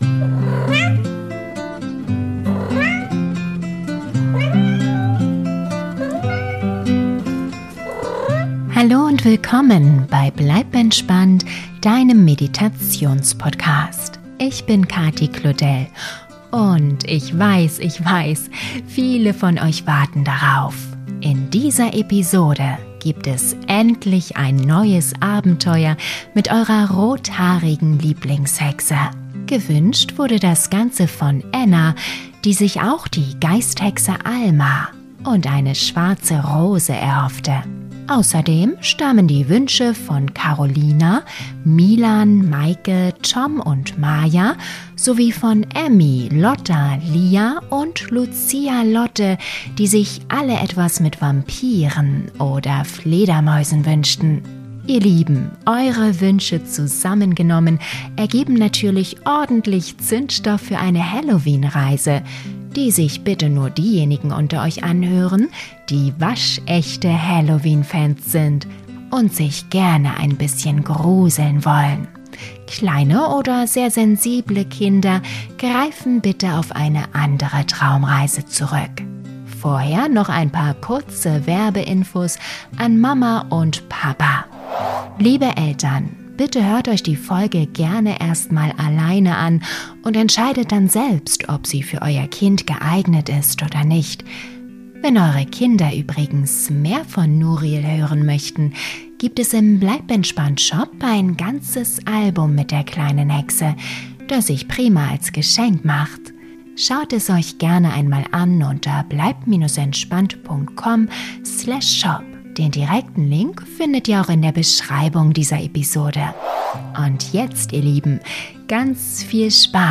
Traumreise für Kinder zum Einschlafen - Hexe Nuriel & die schwarze Rose - Geschichte zu Halloween ~ Bleib entspannt!